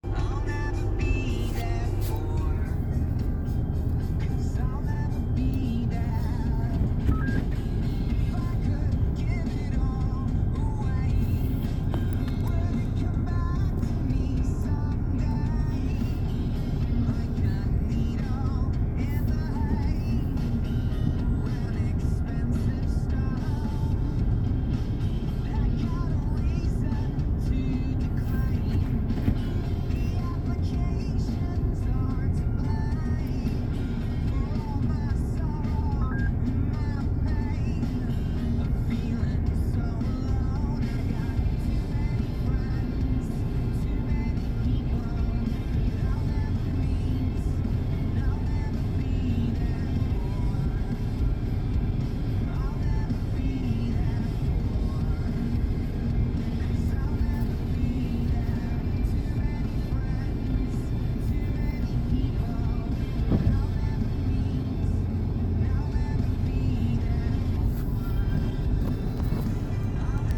Nerviger Piepton - Laberecke - Mondeo MK4 - Community
ich habe seit ein paar Tagen einen nervigen Piepton der mal im 20 Sekundentakt dann mal wieder nach einer halben Stunde